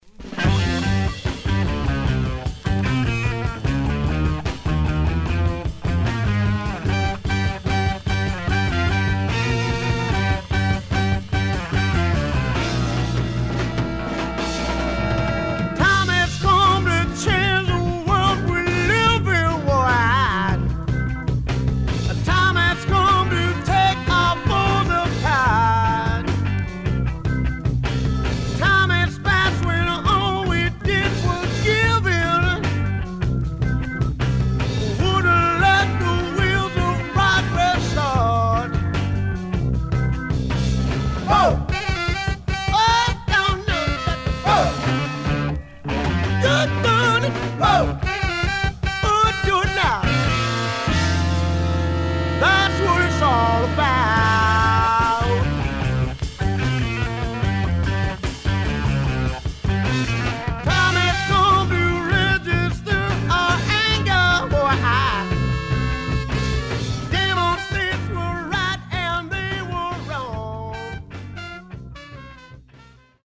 Stereo/mono Mono
Funk